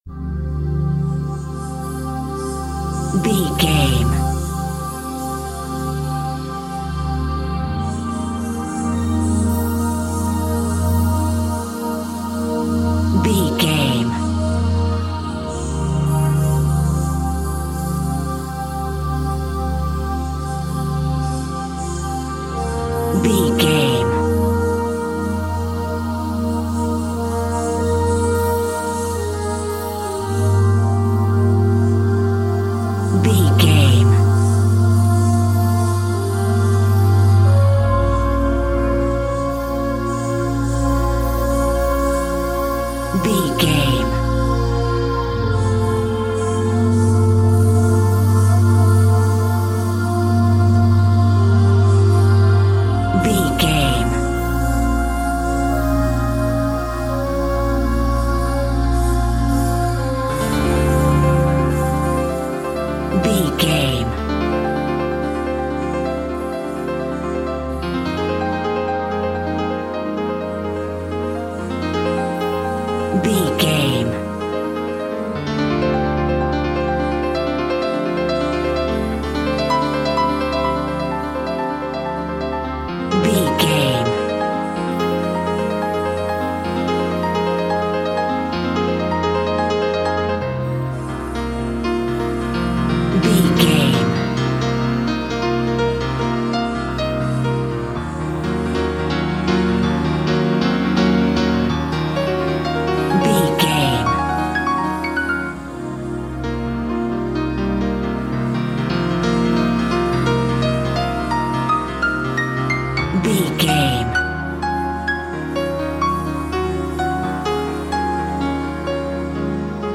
Aeolian/Minor
contemplative
dreamy
meditative
tranquil
melancholic
ethereal
synthesiser
piano